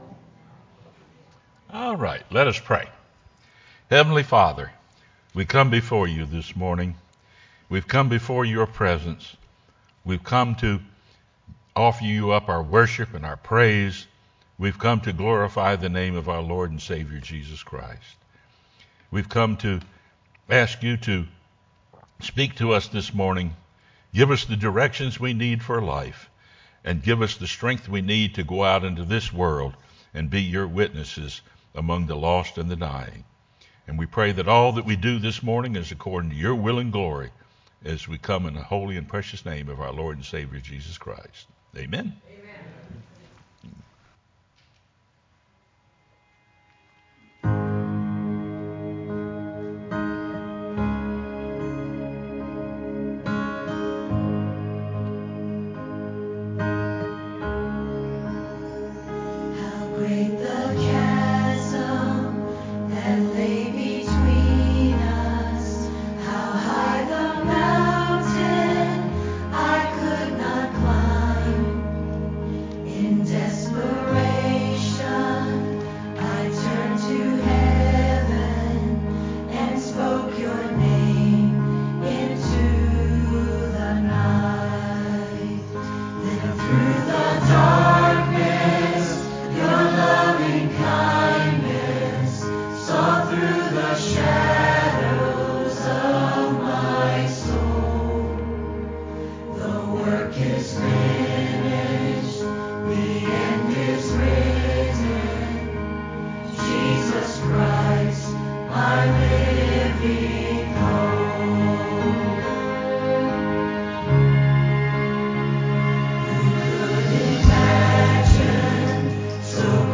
sermonJun22-CD.mp3